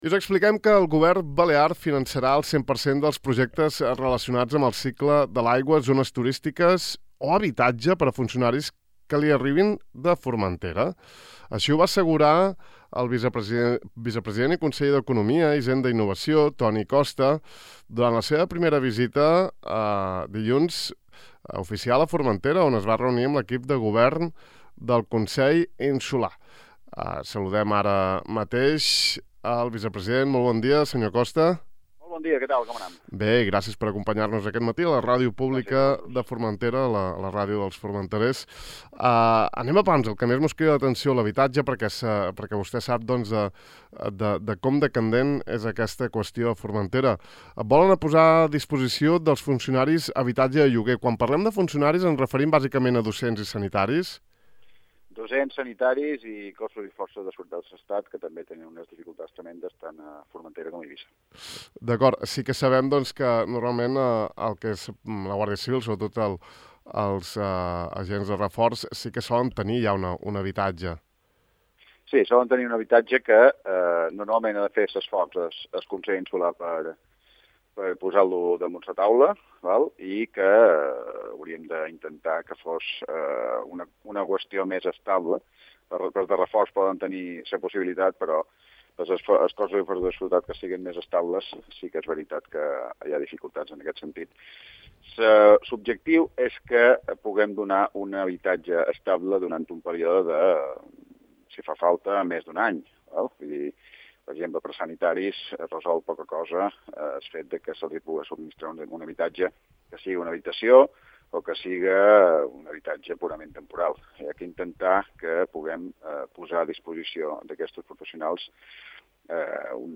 Antoni Costa, vicepresident del Govern Balear i conseller d'Economia, Hisenda i Innovació, ha declarat aquest matí en entrevista al De far a far, que el seu executiu està pensant a llogar habitatge a propietaris de Formentera per, al seu torn, cedir-lo a un preu bonificat a funcionaris destinats a l